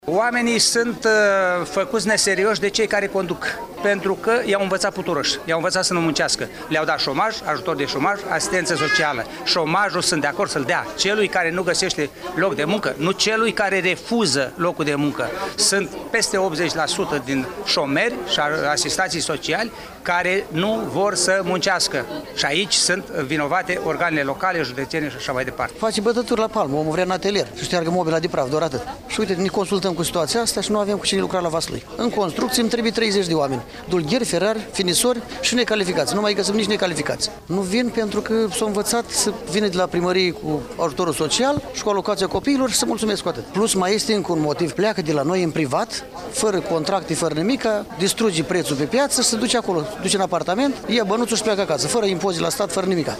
20-apr-rdj-17-Vox-angajatori-Vaslui.mp3